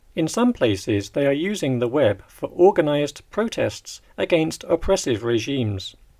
DICTATION 4